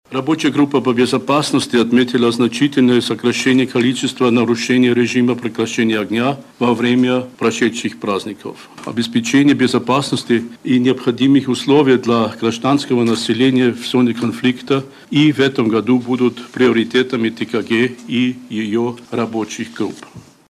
При наличии политической воли стороны конфликта могут прийти к согласию. Заявил по ее результатам  спецпредставитель ОБСЕ в трехсторонней контактной группе Мартин Сайдик.